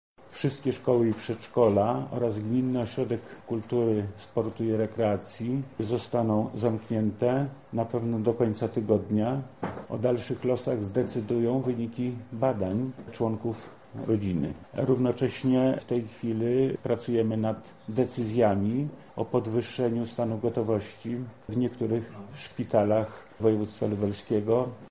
„Mamy pierwszy potwierdzony przypadek zakażenia koronawirusem w naszym województwie” – tak na specjalnej konferencji prasowej poinformował wojewoda lubelski, Lech Sprawka.
— tłumaczy wojewoda lubelski Lech Sprawka